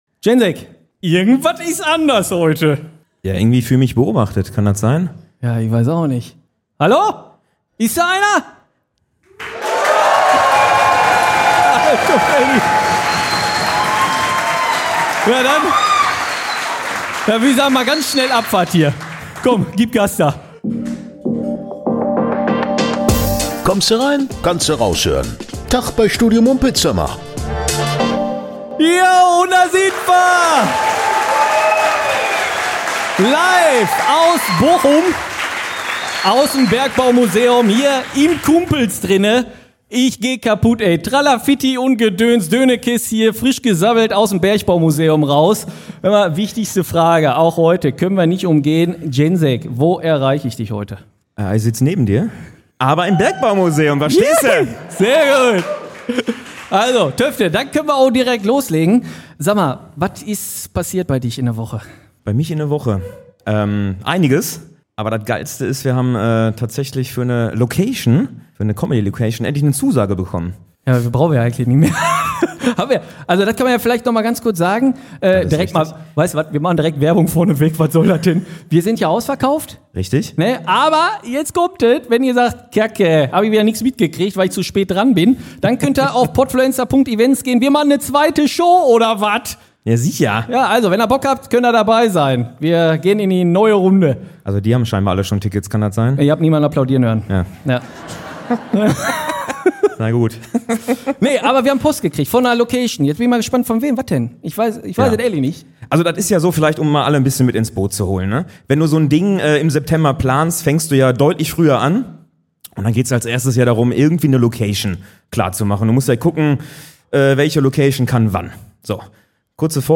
Datt erste Jubiläum zur zehnten Folge vom Studio Mumpitz kommt live ausm Bergbau-Museum: Et dreht sich alles um Drohungen, Liebe, außerirdisches Leben, ne Absage vom Schauspielhaus Bochum und wir beantworten die Fragen der Live-Zuschauer von vor Ort.